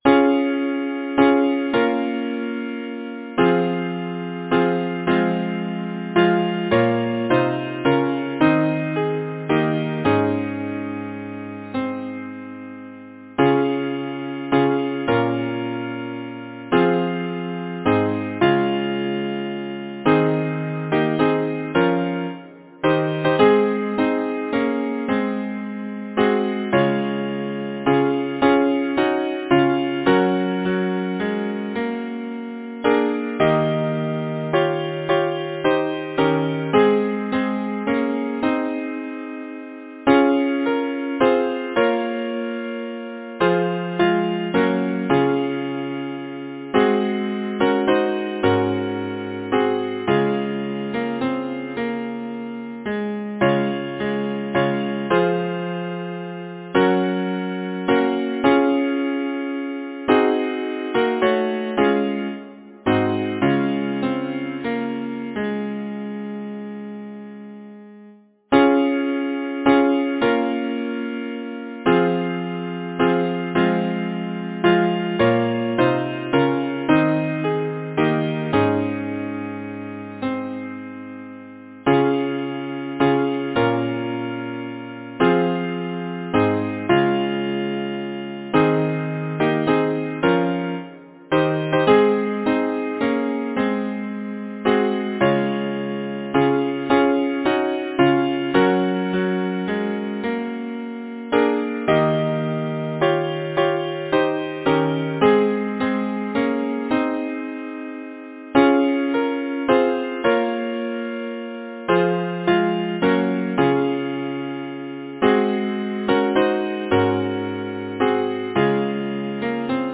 Title: An evening lullaby Composer: Wilfrid Shaw Lyricist: Quinton Ayrtoncreate page Number of voices: 4vv Voicing: SATB Genre: Secular, Partsong
Language: English Instruments: A cappella